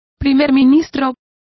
Complete with pronunciation of the translation of premiers.